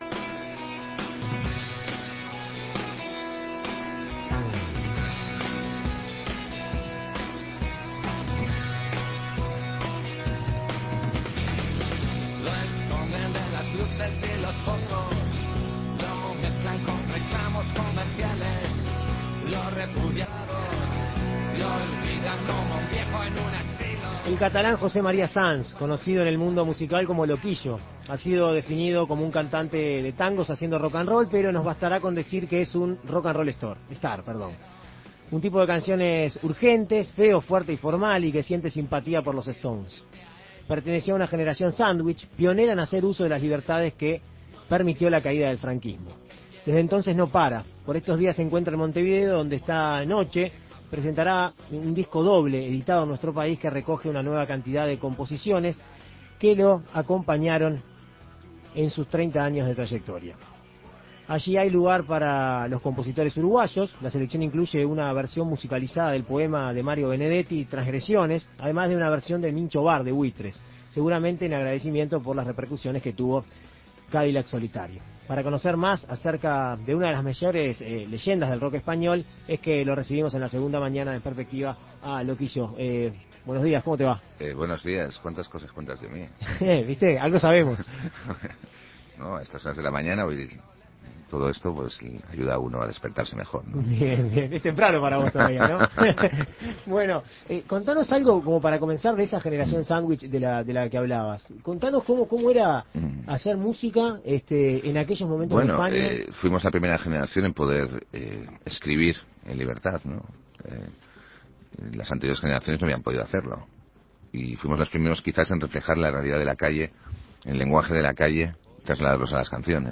Por estos días se encuentra en Montevideo, donde este viernes presentará un disco doble, editado en Uruguay, que recoge una buena cantidad de composiciones que lo acompañaron en sus 30 años de trayectoria. En Perspectiva Segunda Mañana dialogó con el artista.